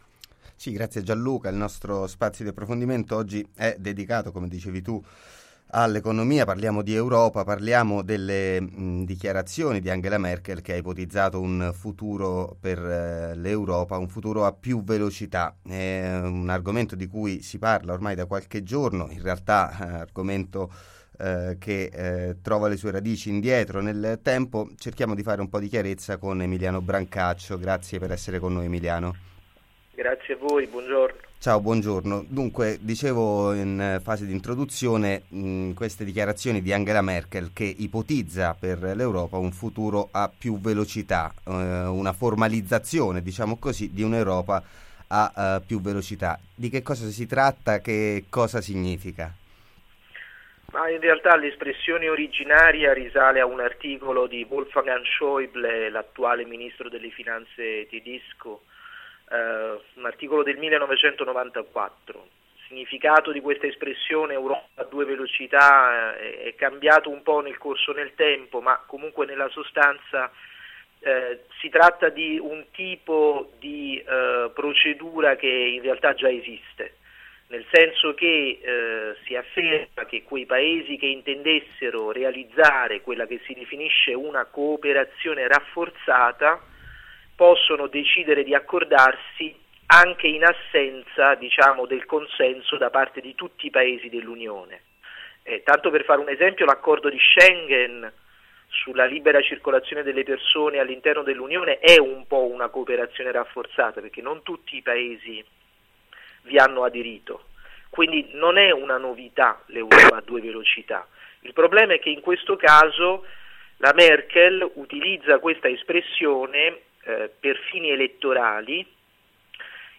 Europa a due velocità: cosa significa veramente? Intervista a Emiliano Brancaccio | Radio Città Aperta